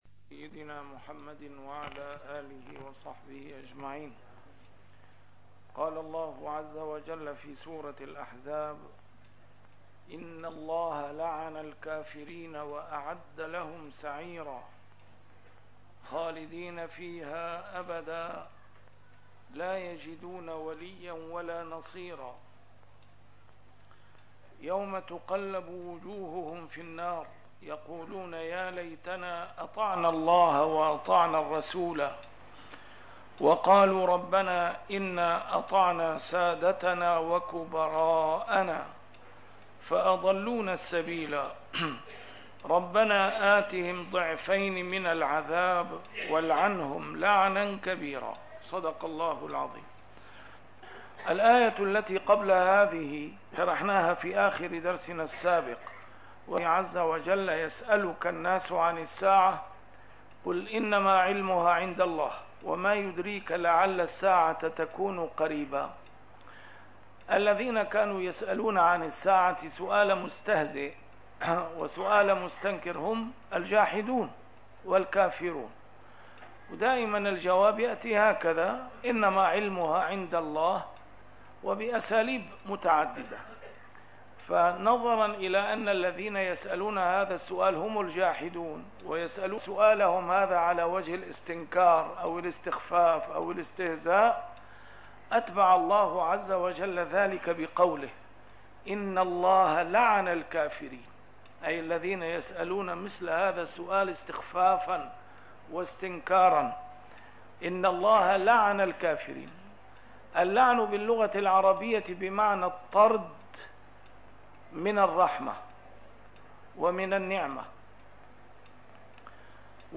A MARTYR SCHOLAR: IMAM MUHAMMAD SAEED RAMADAN AL-BOUTI - الدروس العلمية - تفسير القرآن الكريم - تسجيل قديم - الدرس 384: الأحزاب 64-68